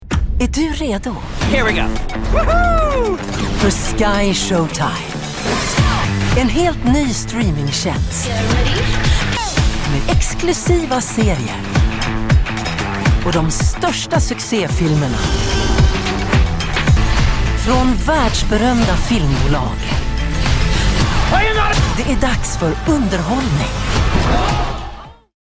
Profundo, Accesible, Maduro, Cálida
Comercial